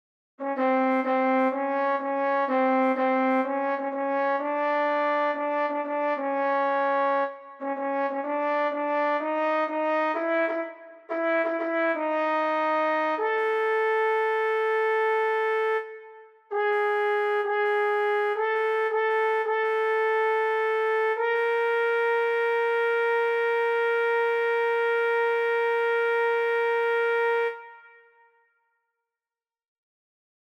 Key written in: F# Major
Type: Barbershop
Each recording below is single part only.